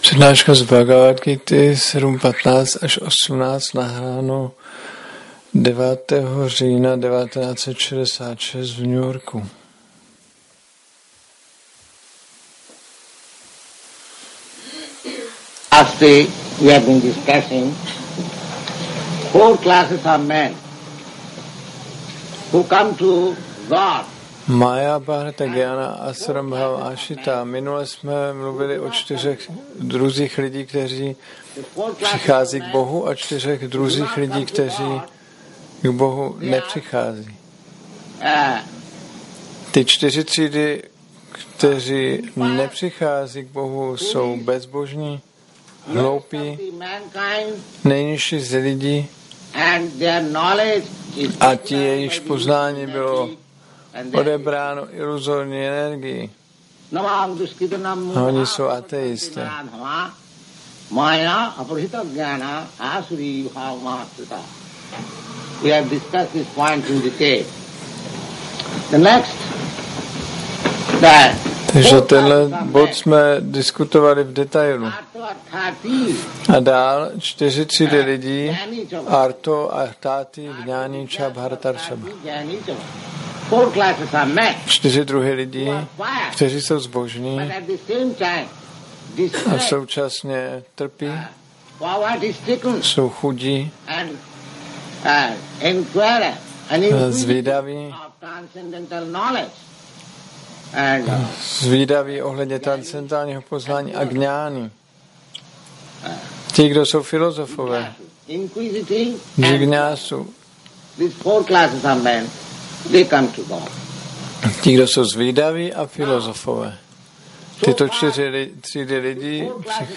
1966-09-09-ACPP Šríla Prabhupáda – Přednáška BG-7.1-18 New York